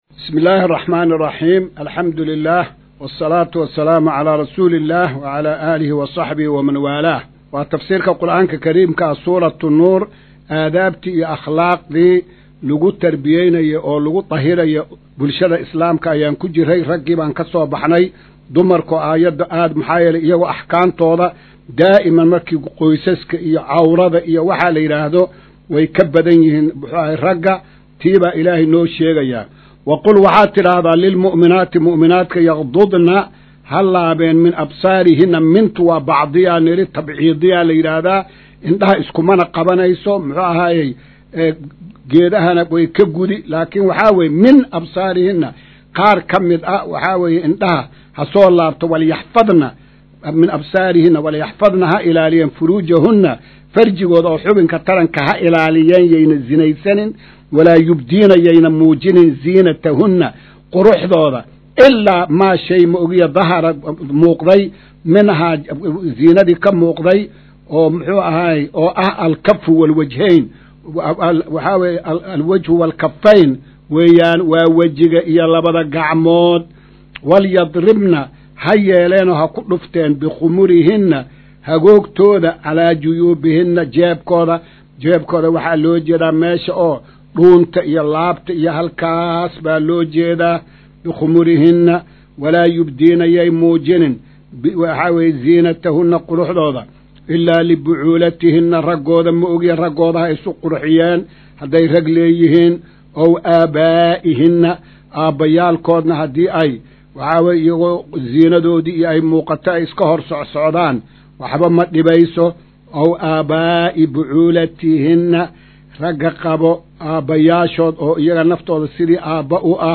Casharka-171aad-ee-Tafsiirka.mp3